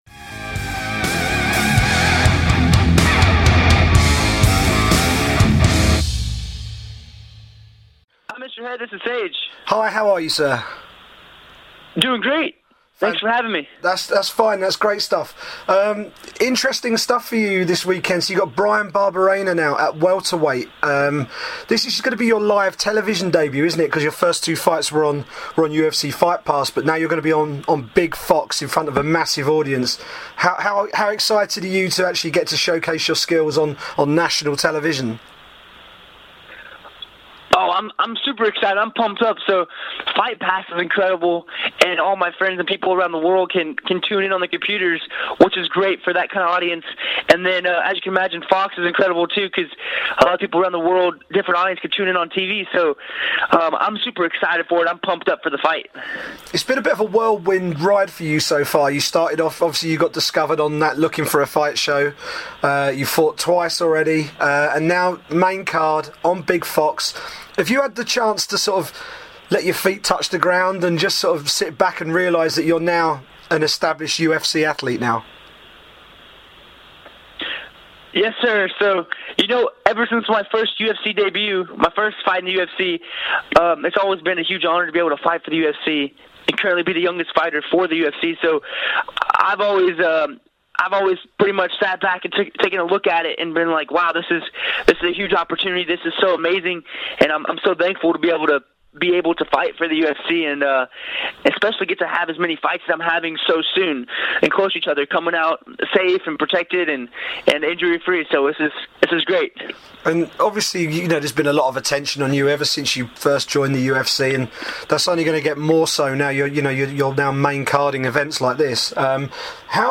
UFC star Sage Northcutt chats to MMA journalist